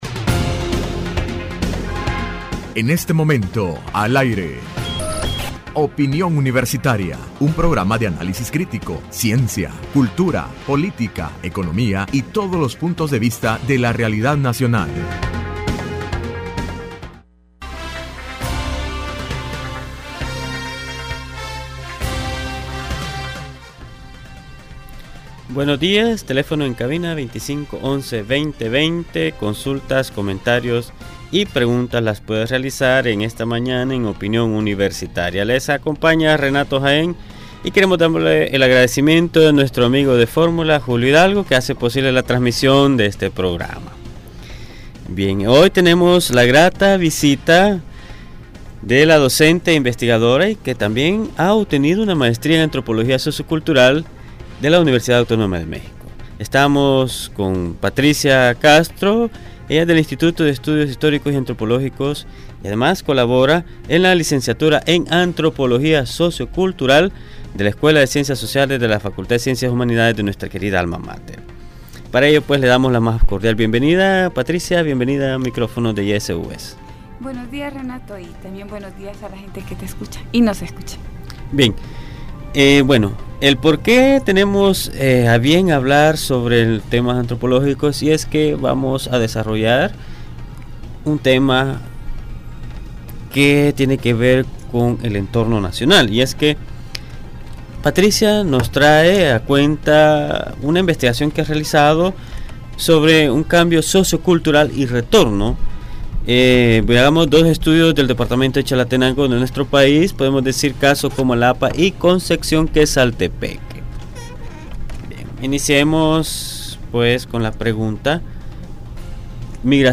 La investigadora y docente